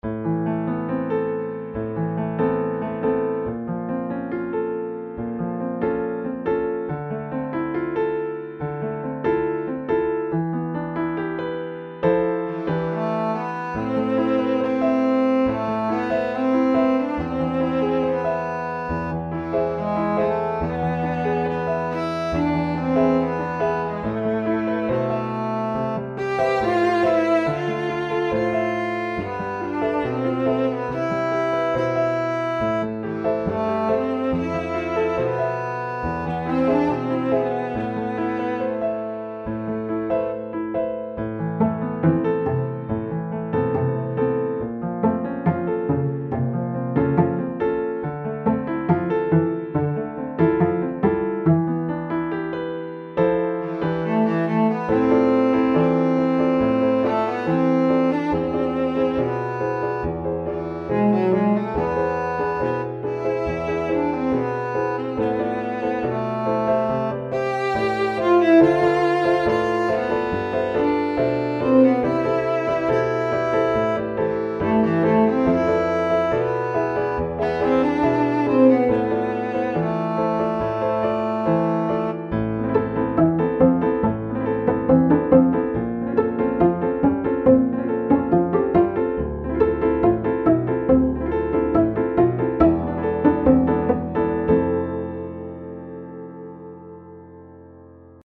- Kleinunternehmer §19 UstG Brazilian Traditional Arr.
Violoncello + Piano Accompaniment Style: Folk / Jazz